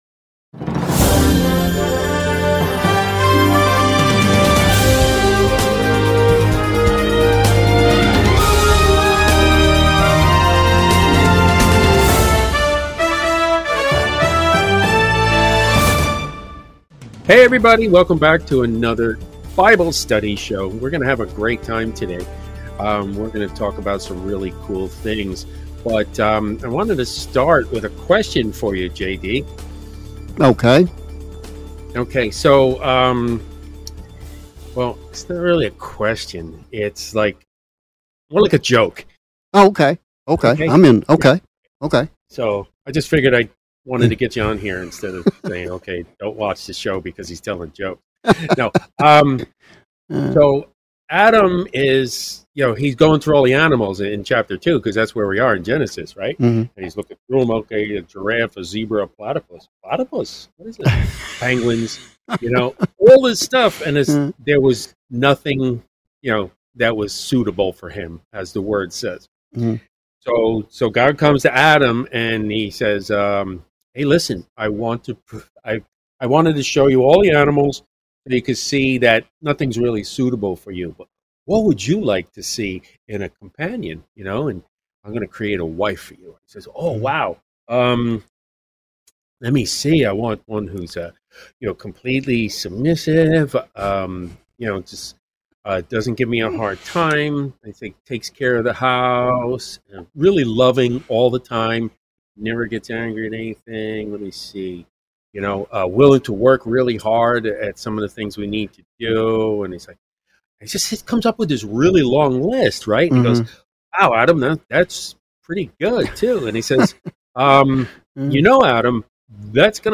God Having CREATED Man, Next MAKES Woman - Weekly Comprehensive Bible Study